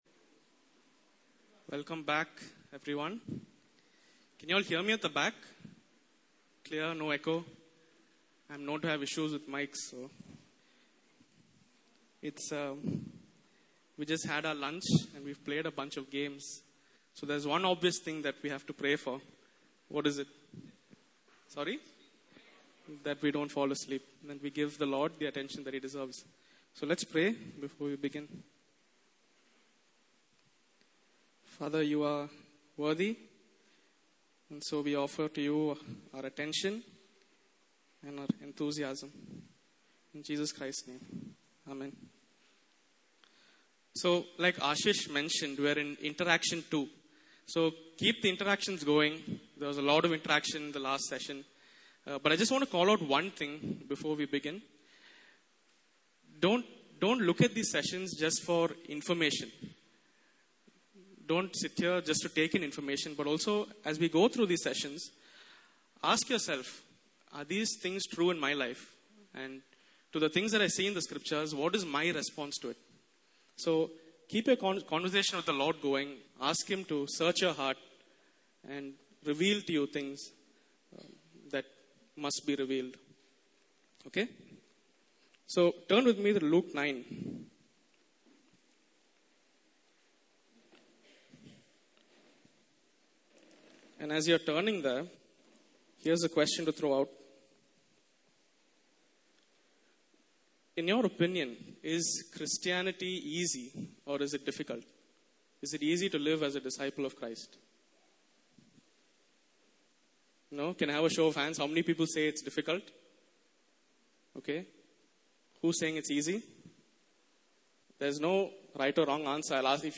Passage: Luke 9:23-24 Service Type: Interactive Session